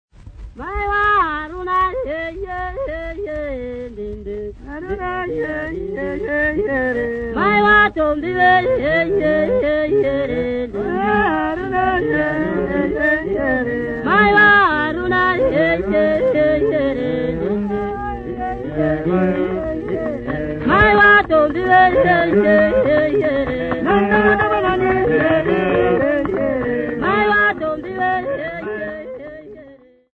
Folk Music
Field recordings
Africa Zimbabwe city not specified f-rh
sound recording-musical
Indigenous music